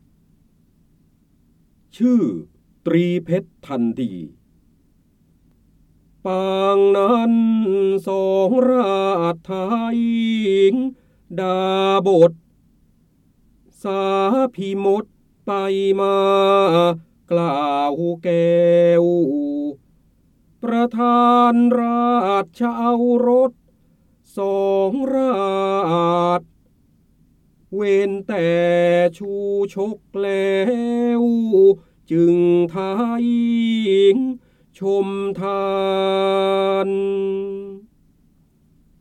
เสียงบรรยายจากหนังสือ จินดามณี (พระโหราธิบดี) ชื่อตรีเพรชทัณฑี
คำสำคัญ : พระโหราธิบดี, ร้อยกรอง, การอ่านออกเสียง, ร้อยแก้ว, พระเจ้าบรมโกศ, จินดามณี